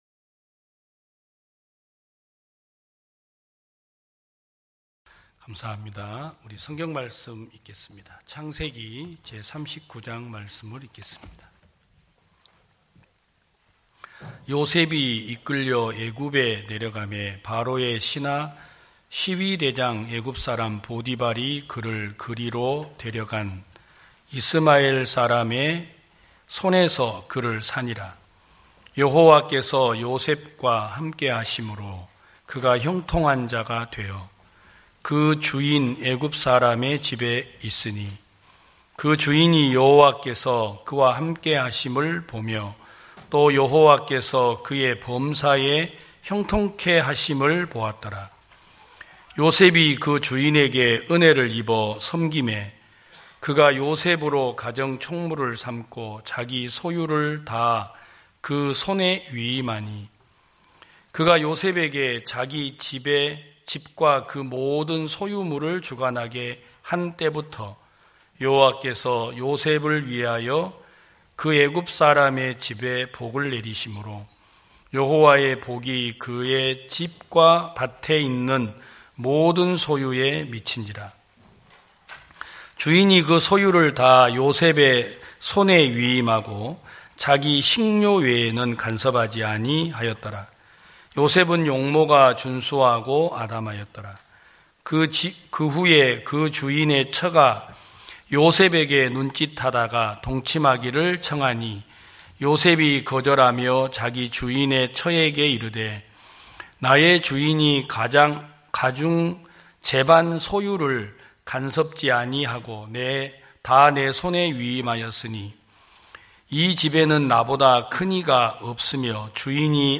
2021년 11월 28일 기쁜소식부산대연교회 주일오전예배
성도들이 모두 교회에 모여 말씀을 듣는 주일 예배의 설교는, 한 주간 우리 마음을 채웠던 생각을 내려두고 하나님의 말씀으로 가득 채우는 시간입니다.